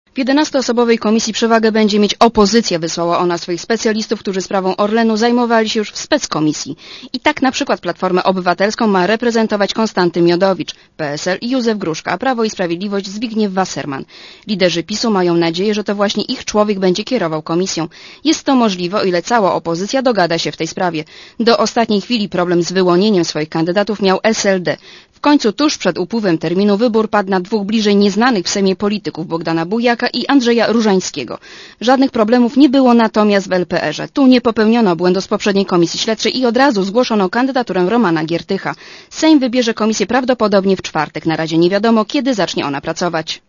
Relacja reportera Radia ZET (169kB)